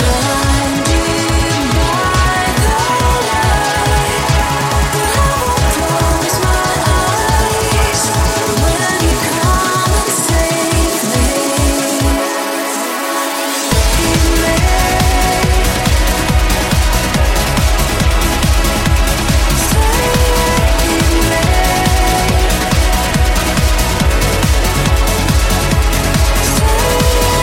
Genere: uplifting trance, (preascolto a 140bpm)